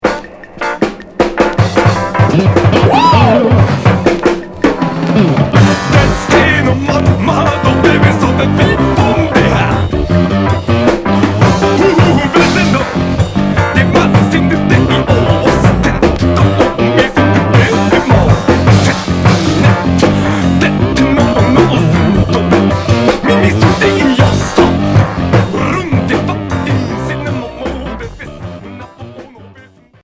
Free rock 45t promo